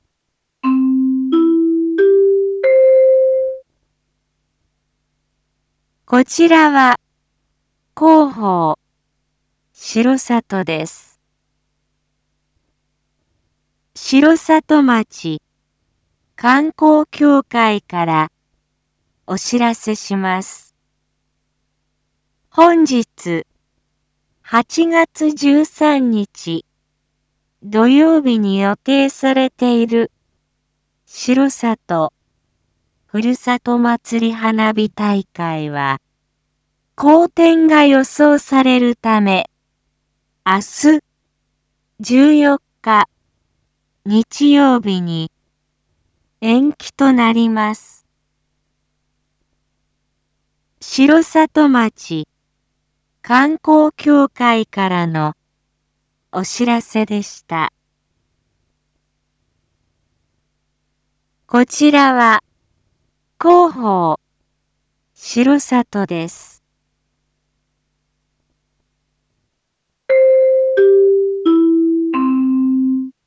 Back Home 一般放送情報 音声放送 再生 一般放送情報 登録日時：2022-08-13 07:01:15 タイトル：R.8.13 7時放送分 インフォメーション：こちらは広報しろさとです。